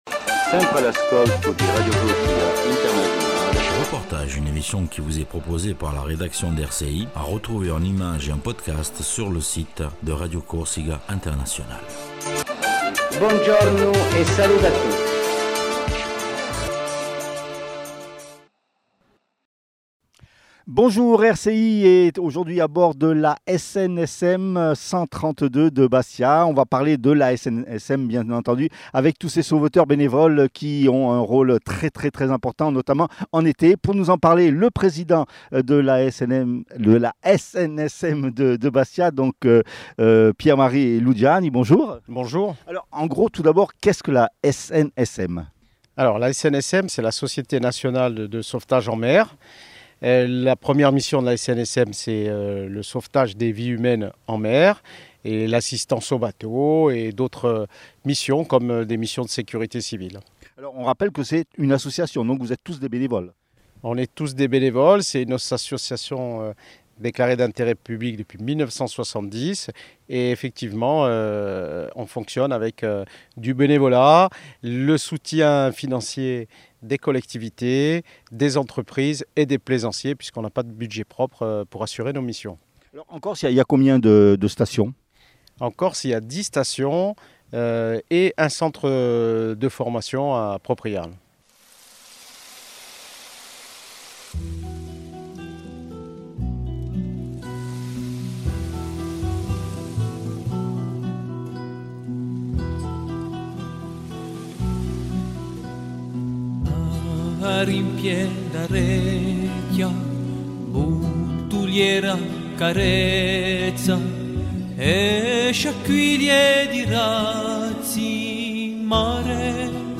REPORTAGE SUR LA SNSM DE BASTIA